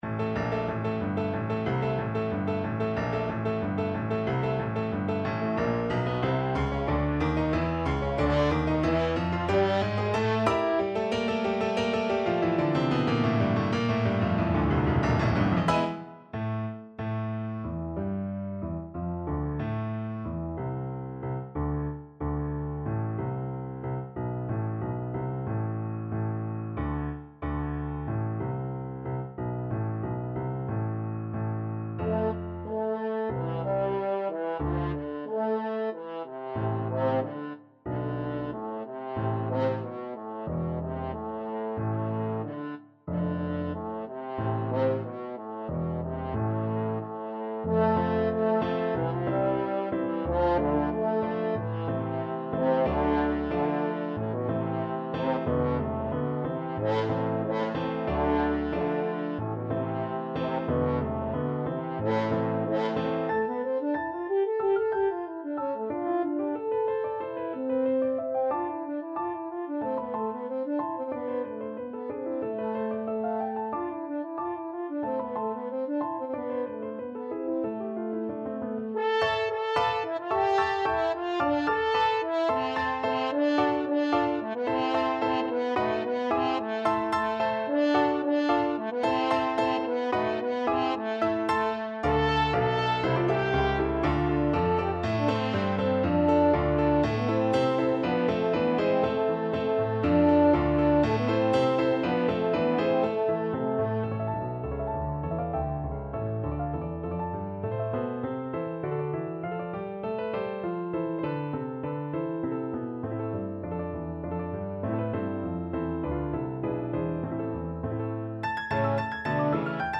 Allegro =92 (View more music marked Allegro)
2/4 (View more 2/4 Music)
Classical (View more Classical French Horn Music)